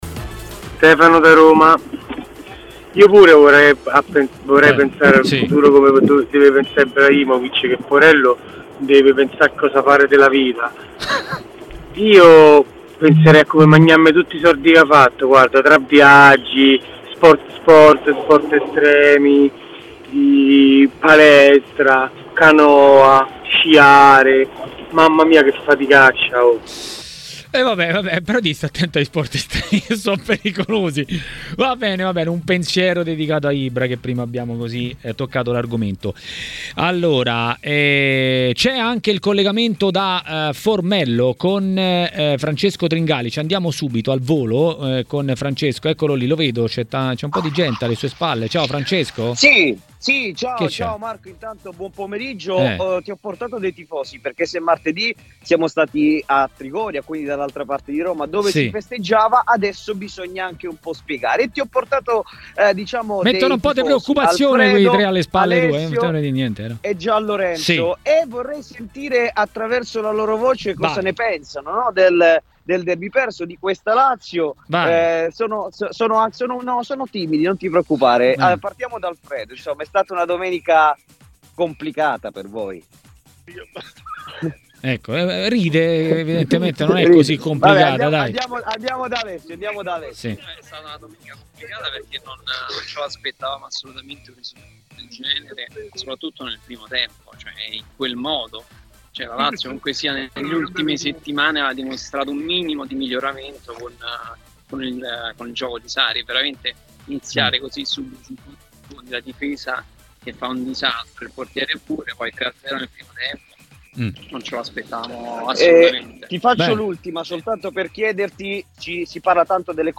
nel pomeriggio di TMW Radio, ha parlato dei temi di maggiore attualità.